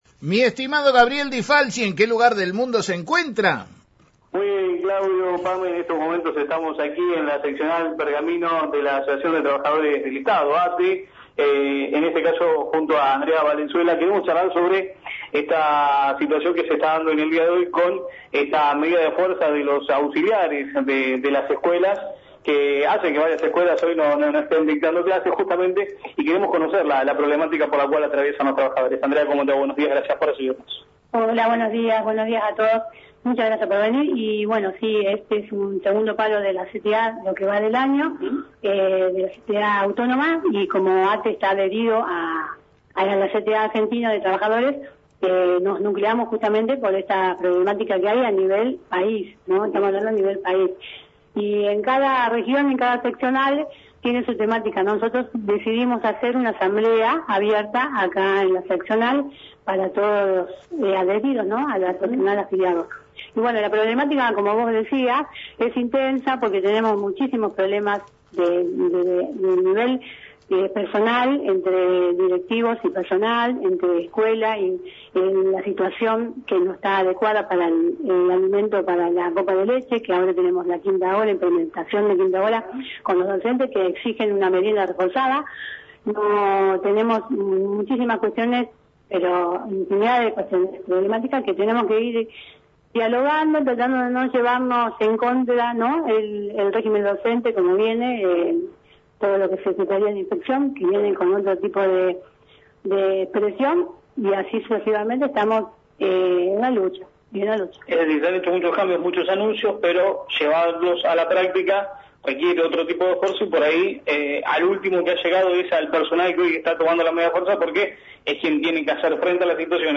desde el móvil de «La Mañana de la Radio»